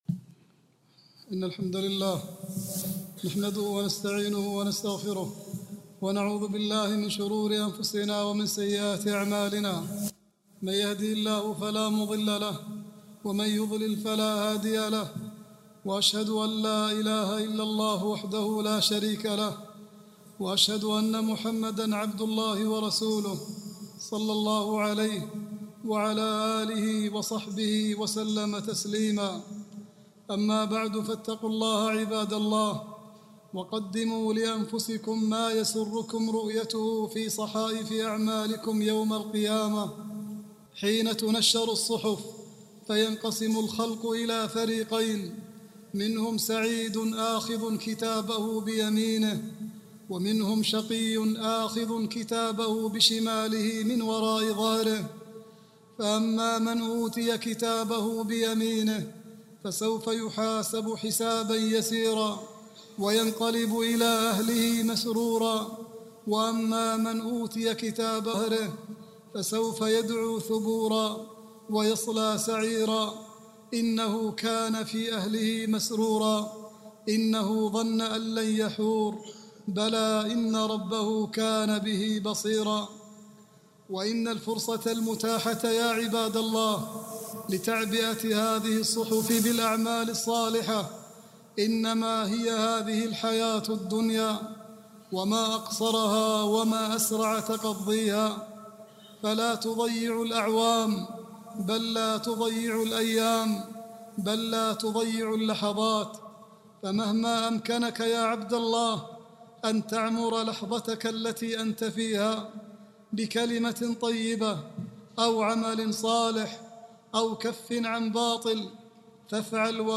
العنوان : الحث على الصوم في شعبان والتحذير من بدعة الاحتفال بليلة المعراج (خطبة)
khutbah-4-8-39.mp3